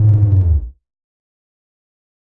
描述：与"Attack Zound02"相似，但音调较低。这个声音是用Cubase SX中的Waldorf Attack VSTi制作的。
Tag: 电子 SoundEffect中